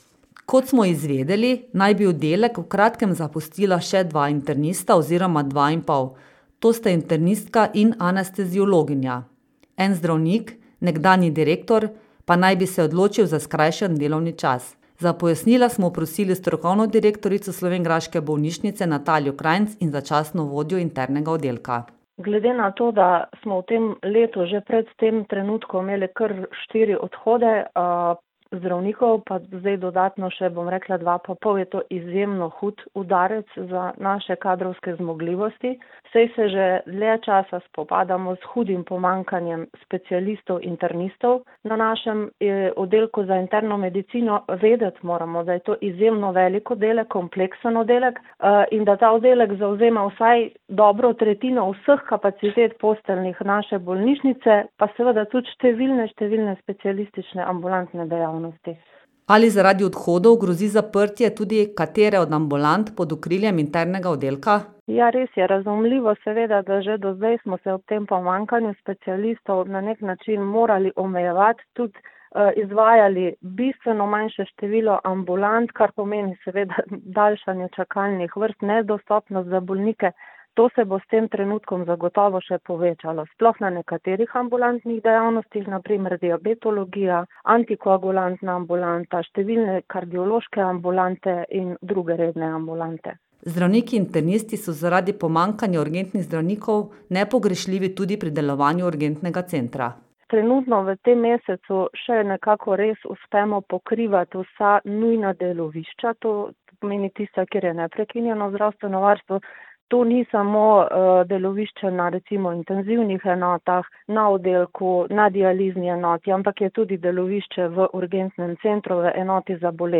Lokalne novice | Koroški radio - ritem Koroške